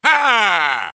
One of Wario's voice clips in Mario Kart Wii